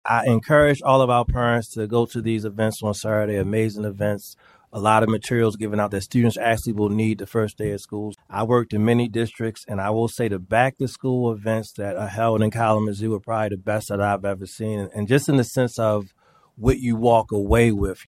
Superintendent Darrin Slade says he is really impressed by Kalamazoo’s efforts to help students get ready for school.